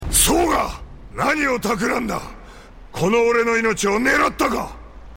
Apparently some people complained about Raoh sounding too soft in the cinematic version.